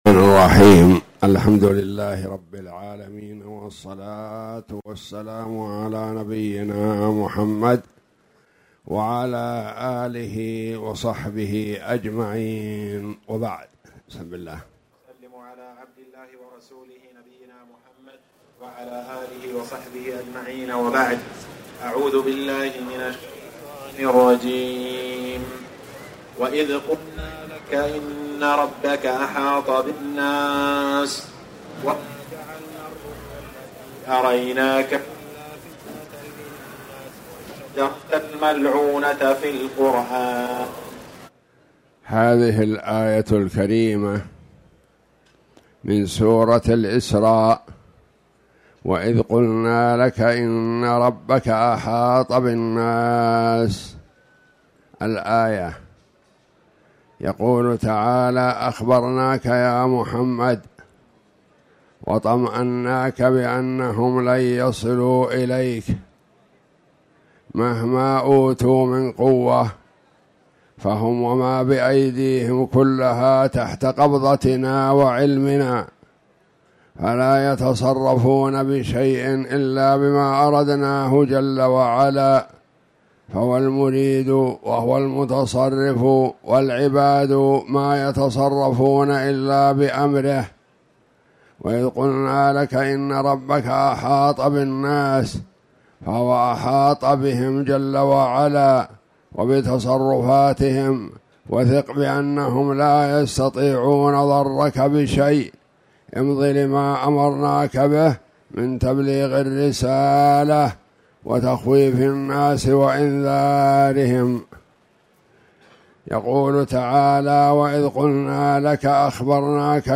سورة الإسراء --مقطع الصوت قليلا - الموقع الرسمي لرئاسة الشؤون الدينية بالمسجد النبوي والمسجد الحرام
تاريخ النشر ١٨ رمضان ١٤٣٧ هـ المكان: المسجد الحرام الشيخ